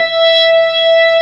55O-ORG18-E5.wav